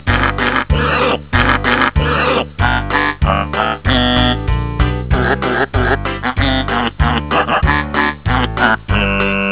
Pigs Singing